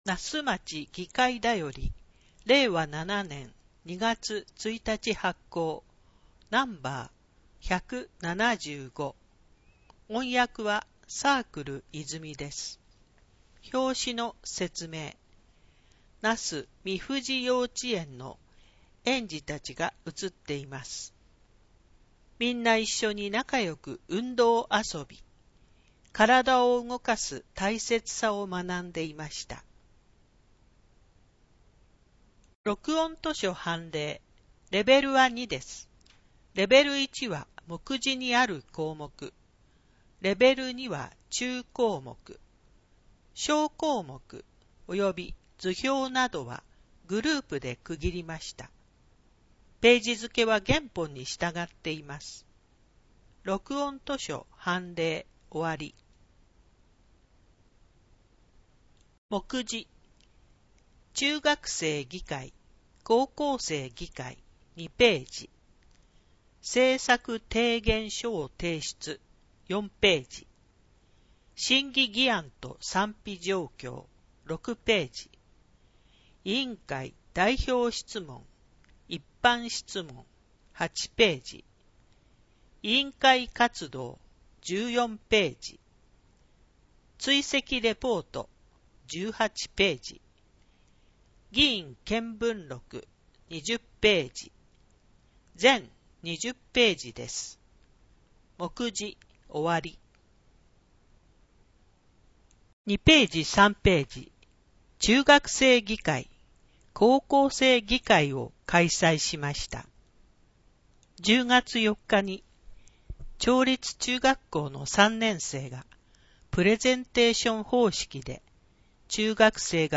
なすまち議会だより（音訳版）
なすまち議会だよりは、音訳ボランティア団体「サークル泉」の協力のもと、音訳版を作成しております。
議会だより175号（音訳）.mp3